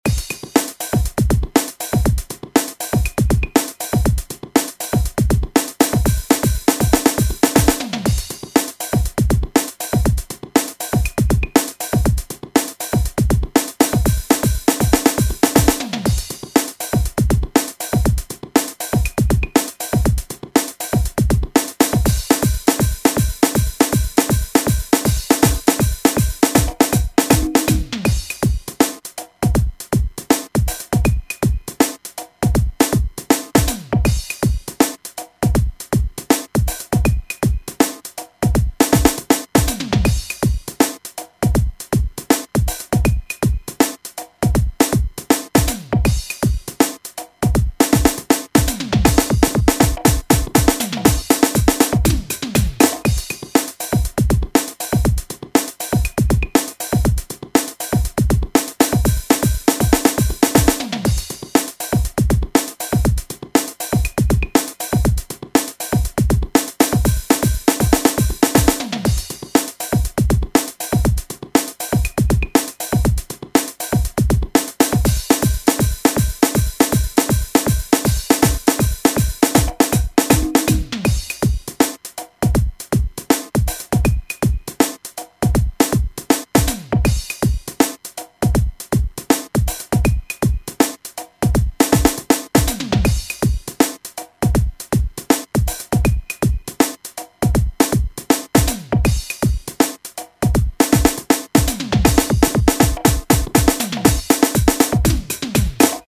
実習でMIDIを作らされて、その時に作ったものがあります。
最初１６ビートだけど途中でハーフシャッフルになる。
でまた１６ビートに戻って、みたいな。